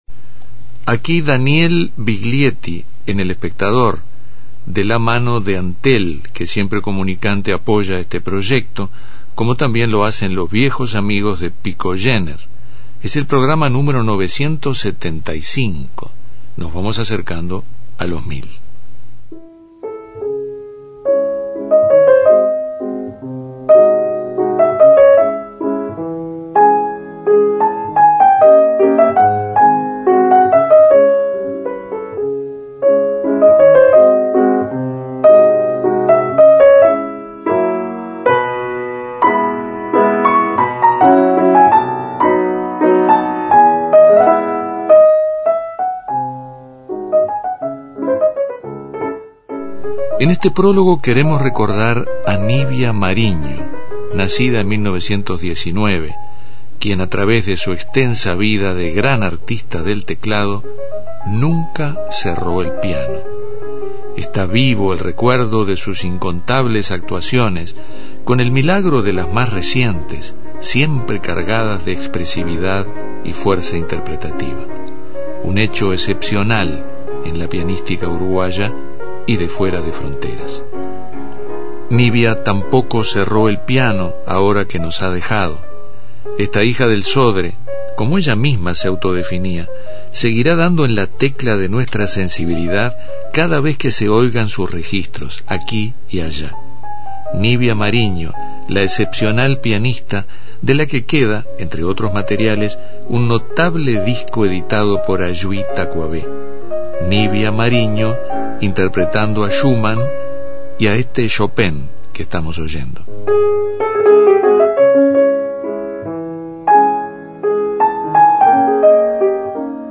El 11 de setiembre de 1927 nace en Cerro Largo, entre grillos y terrones, el inolvidable payador Carlos Molina. Tímpano aborda el recuerdo del insigne cantor nuestro e incluye pasajes de una de las entrevistas que le realizó Viglietti.